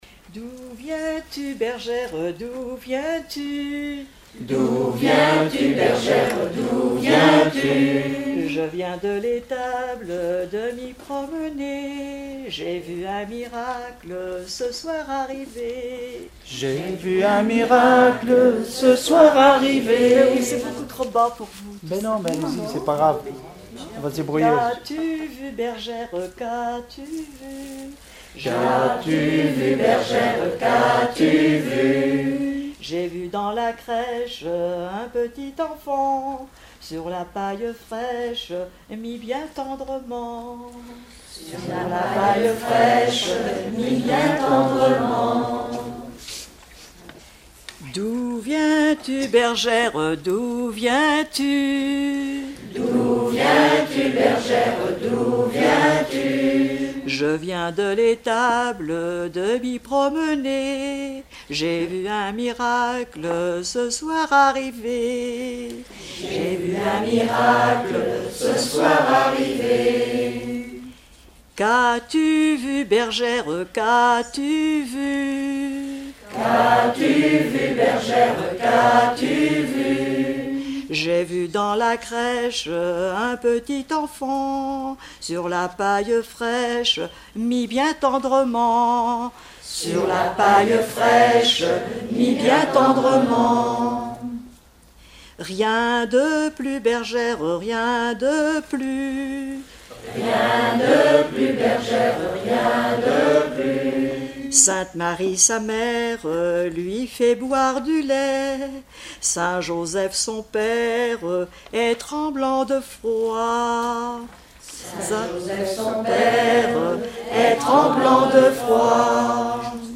Mémoires et Patrimoines vivants - RaddO est une base de données d'archives iconographiques et sonores.
Chansons traditionnelles et populaires
Pièce musicale inédite